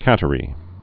(kătə-rē)